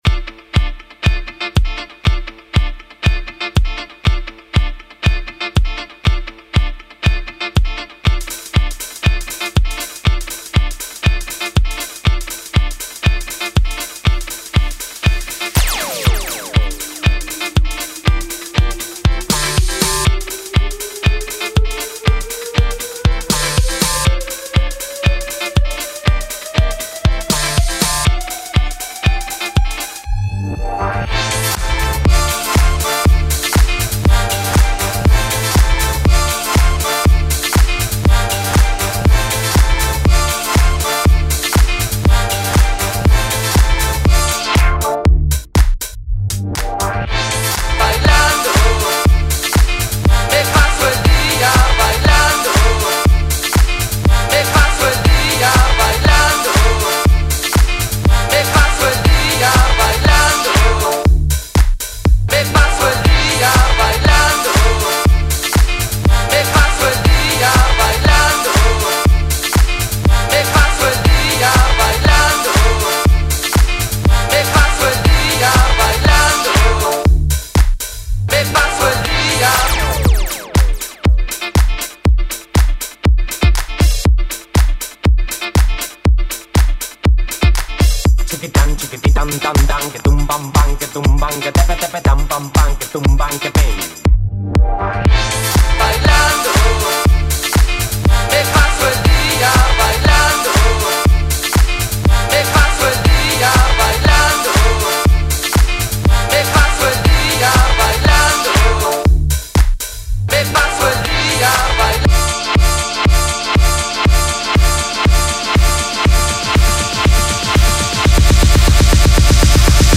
mashup
(Acapella)